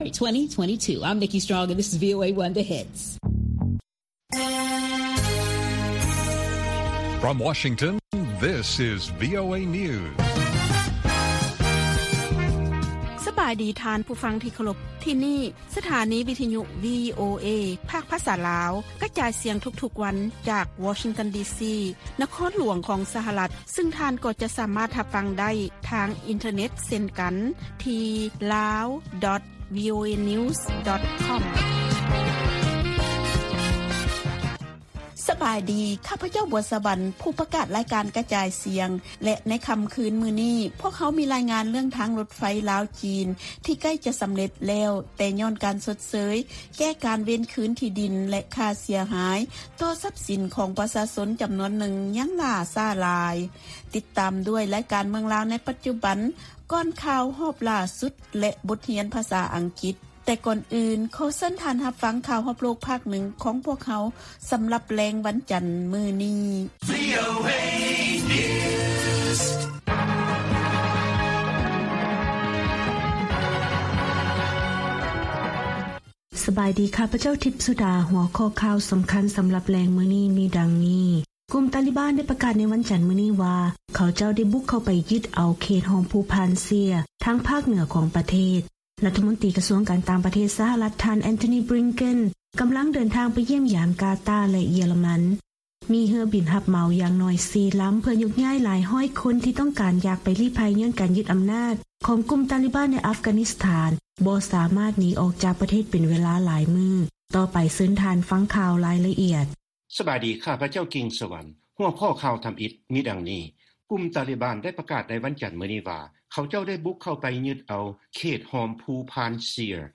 ວີໂອເອພາກພາສາລາວ ກະຈາຍສຽງທຸກໆວັນ.